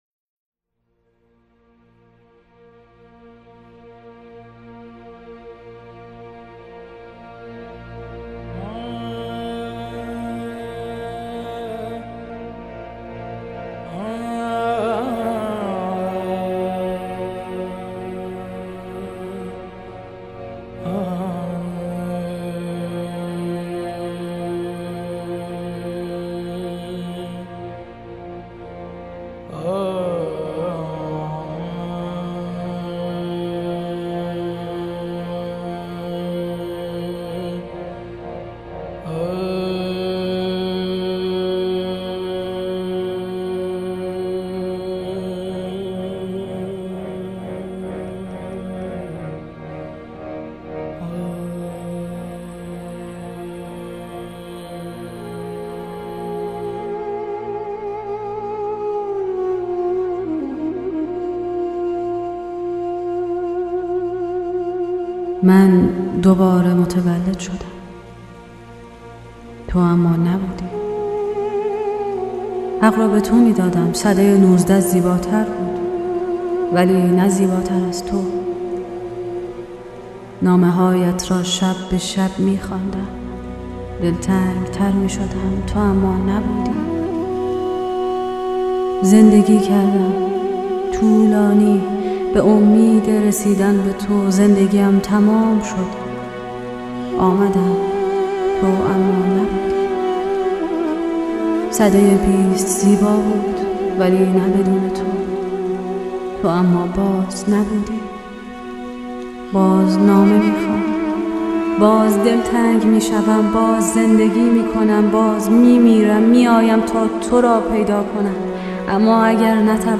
اطلاعات دکلمه